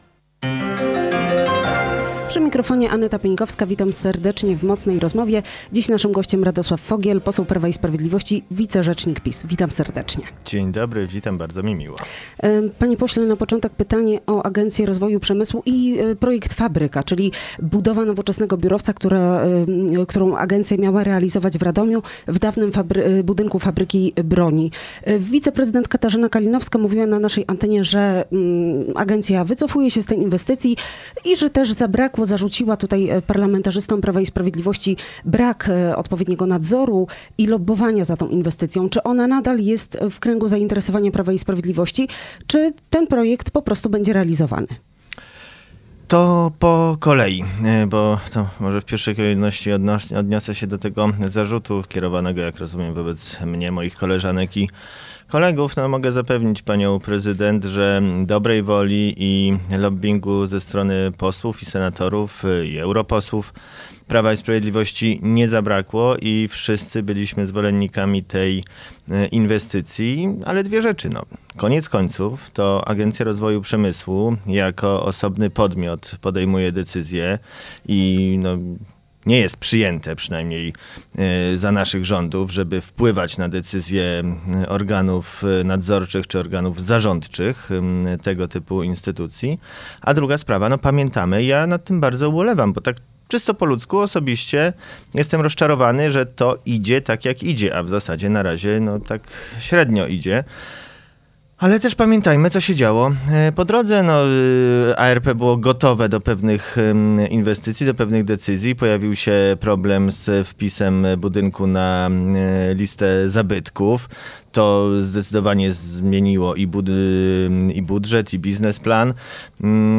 Poseł, zastępca rzecznika prasowego PiS, Radosław Fogiel był gościem